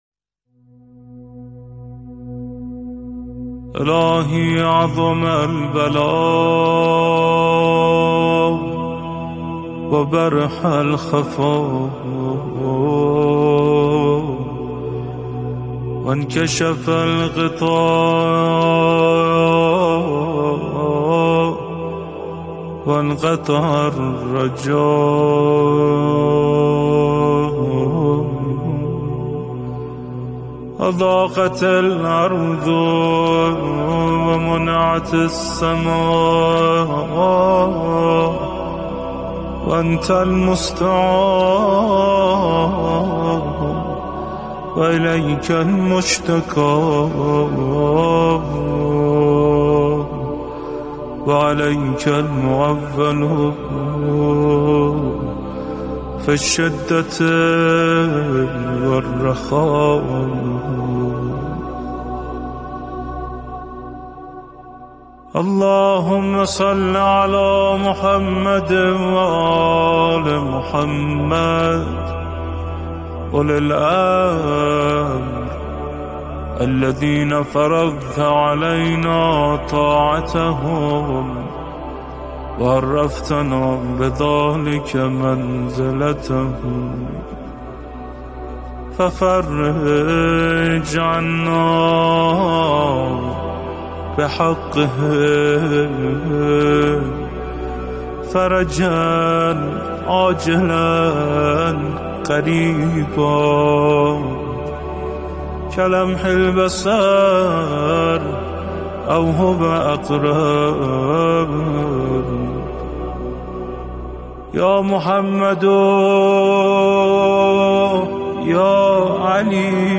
دعای «فرجا عاجلًا قریبًا» با اجرای علی فانی